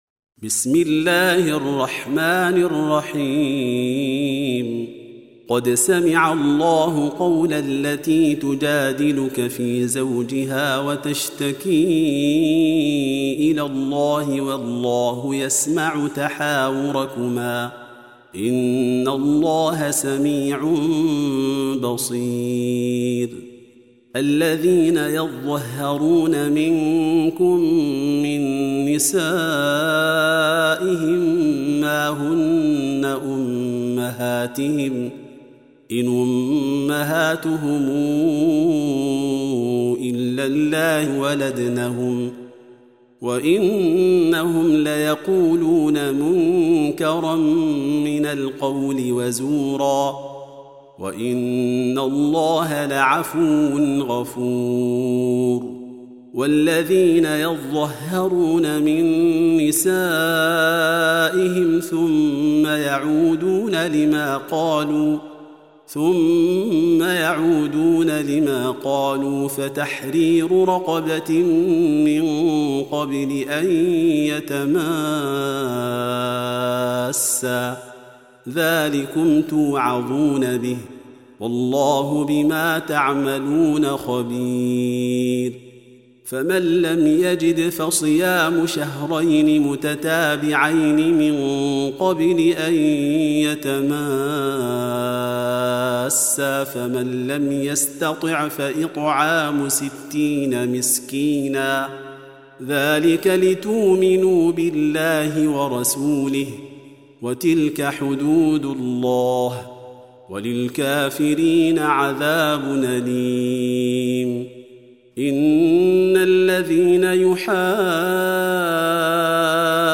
Reciting Murattalah Audio for 58. Surah Al-Mujâdilah سورة المجادلة N.B *Surah Includes Al-Basmalah